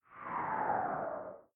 magic_crumple3.ogg